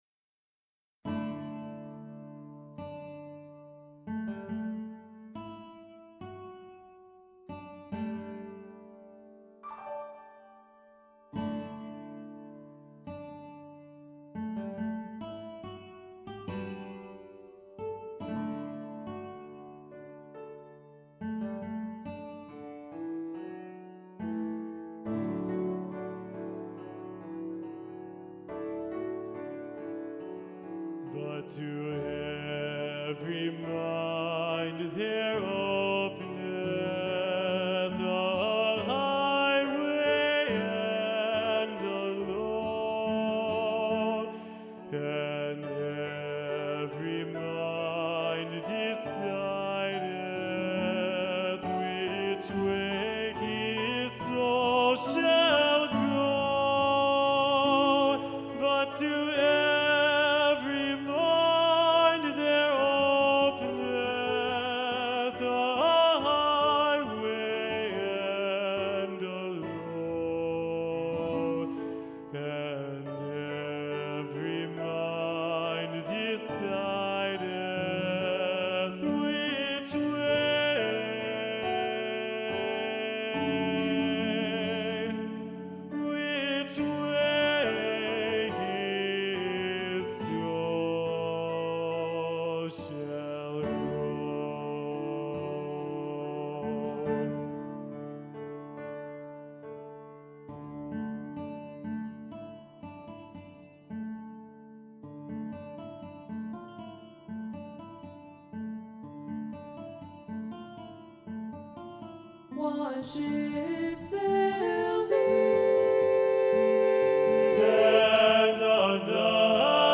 Voicing: "SATB","Solo"